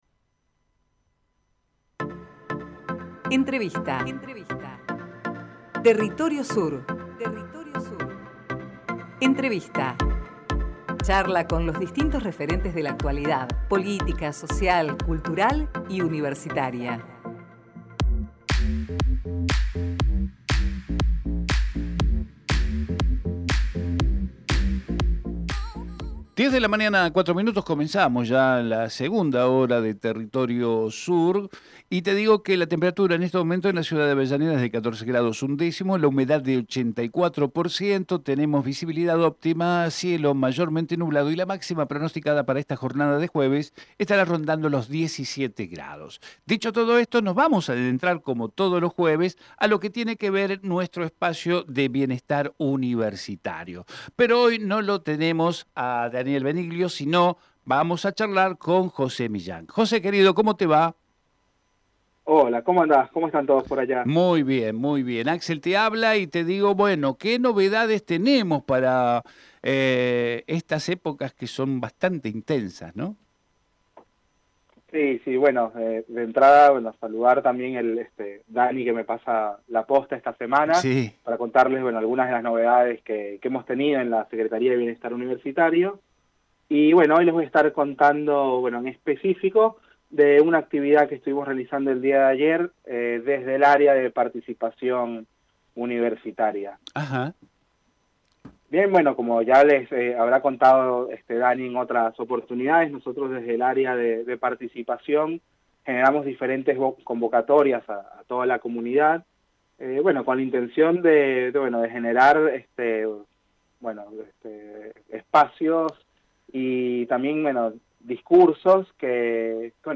Compartimos con ustedes la entrevista